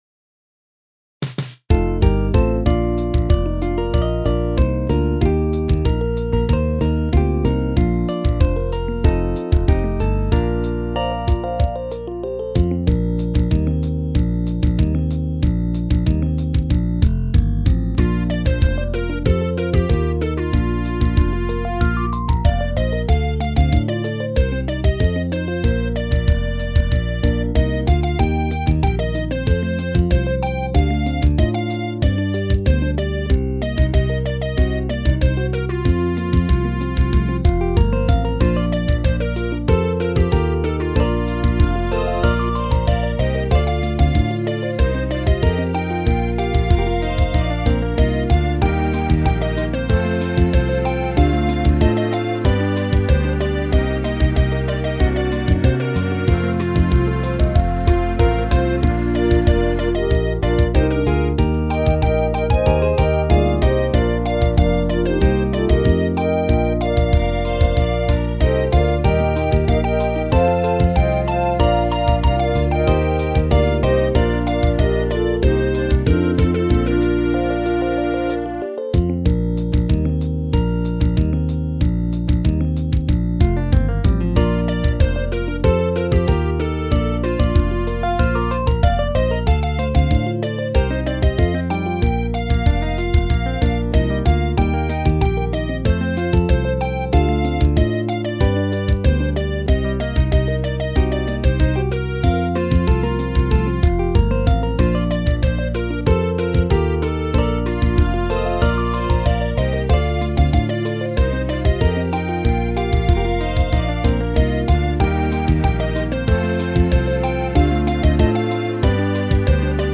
私としてはカントリー系は速攻で片付けないと。
尾ひれ系はピアノとギター音のみで対応。